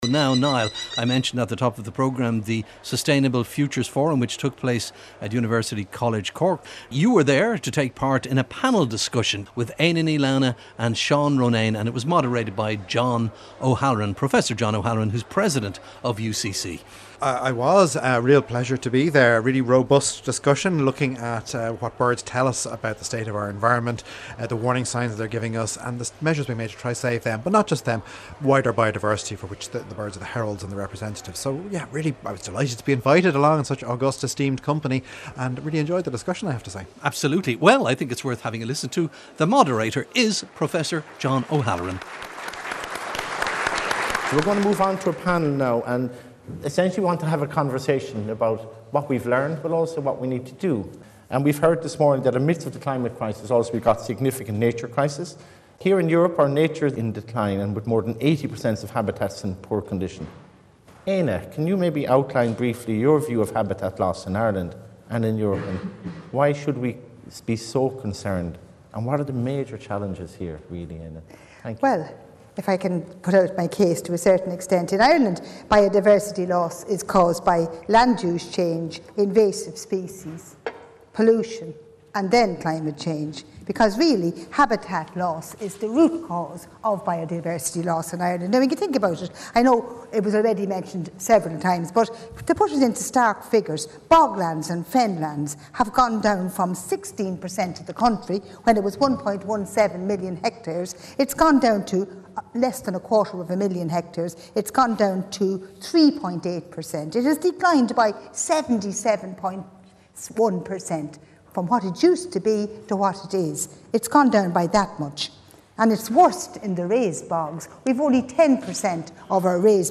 Derek Mooney and guests explore the natural world in all its forms.